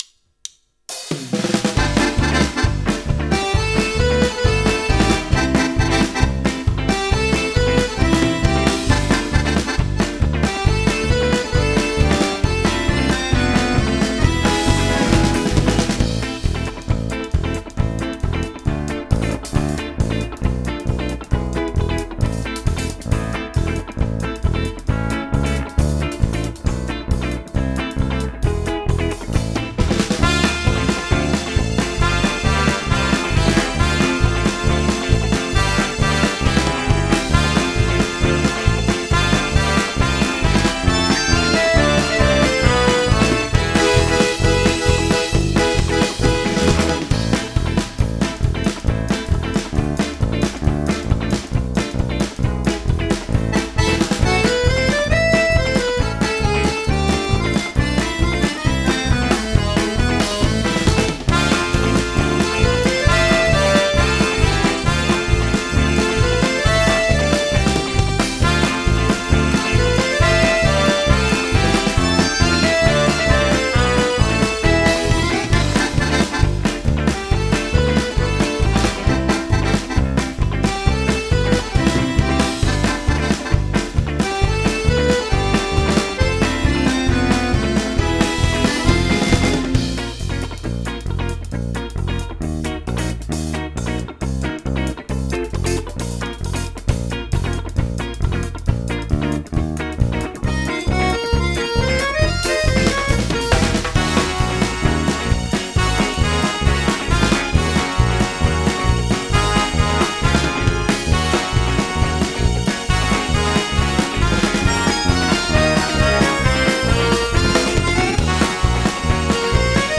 Репетиционный лайв моих коллег, чётко в -14. По лимитру попало 1дб гр, и то с учётом того, что я селлинг выставил -1дб.